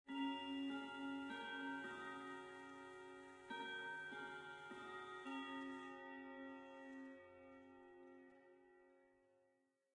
Clock1.ogg